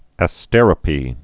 (ă-stĕrə-pē)